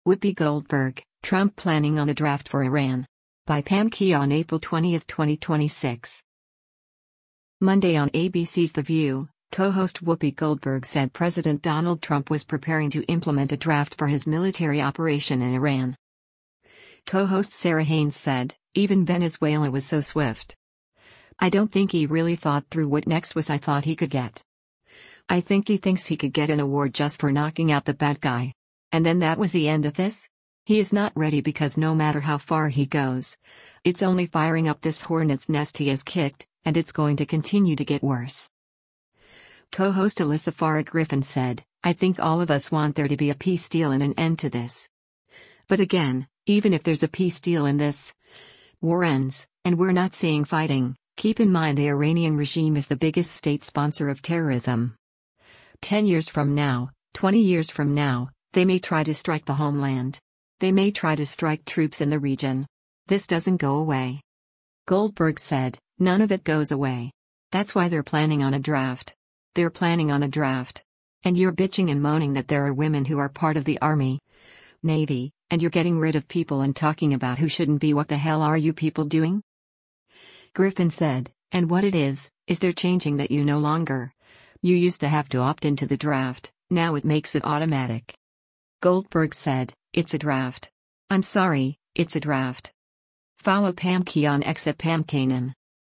Monday on ABC’s “The View,” co-host Whoopi Goldberg said President Donald Trump was preparing to implement a draft for his military operation in Iran.